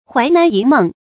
槐南一夢 注音： ㄏㄨㄞˊ ㄣㄢˊ ㄧ ㄇㄥˋ 讀音讀法： 意思解釋： 比喻人生如夢，富貴得失無常 出處典故： 明 王錂《春蕪記》：「正是只將桑海千秋事，付與 槐南一夢 中。」